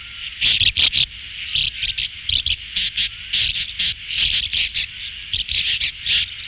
Hirondelles de rivage, riparia riparia
Hirondelle de rivage